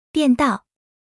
audio_lanechange.wav